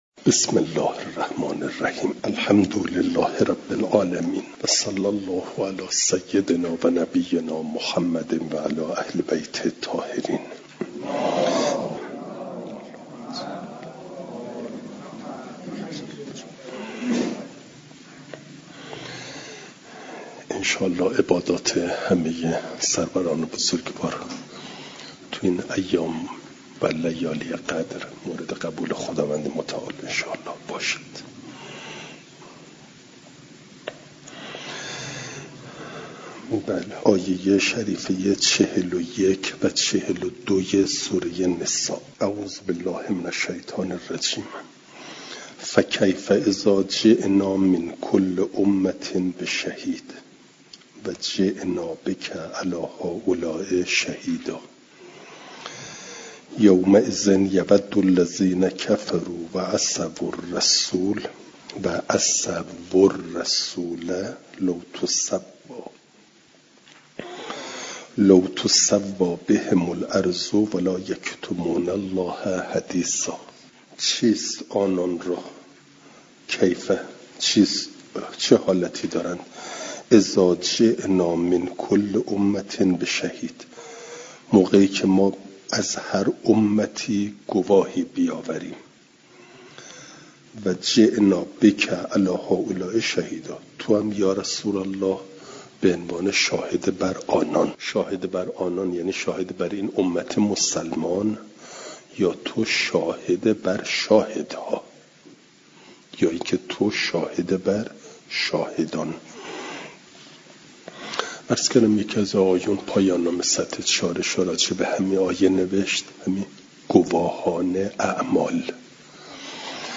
جلسه سیصد و شصت و دوم درس تفسیر مجمع البیان